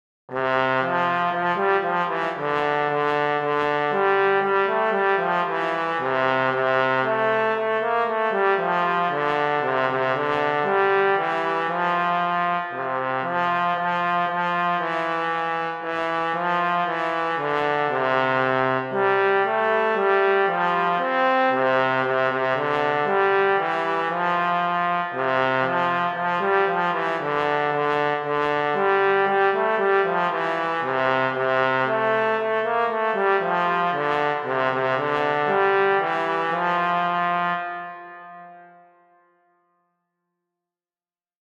für Posaune solo, Noten und Text als pdf, Audio als mp3